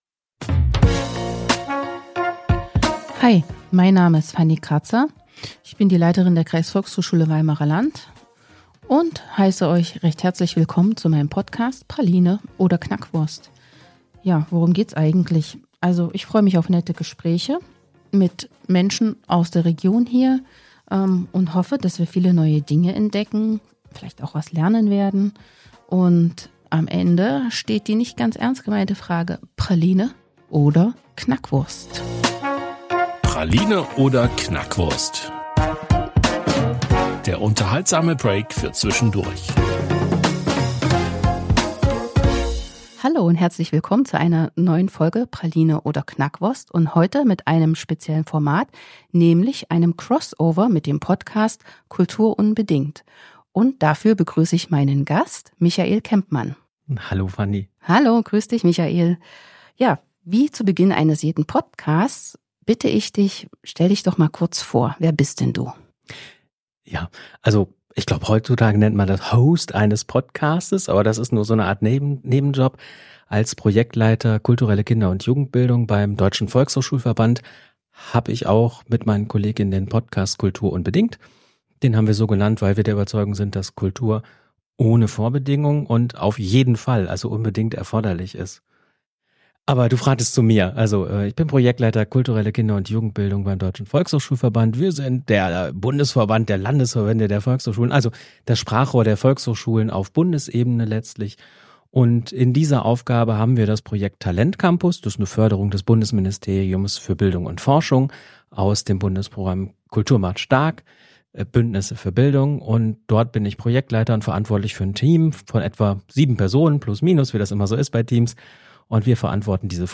Mit "Praline oder Knackwurst" lockt die Kreisvolkshochschule Weimarer Land interessante Gäste vor das Mikrophon, die bei der KVHS eingecheckt haben oder mit denen die KVHS zusammenarbeitet.
Heute gibt es wieder ein Special, nämlich ein Crossover mit dem Podcast "Kultur unbedingt" des Deutschen Volkshochschulverbands, DVV. In dieser Episode dreht sich alles um das Bildungsprogramm **talentCAMPus**.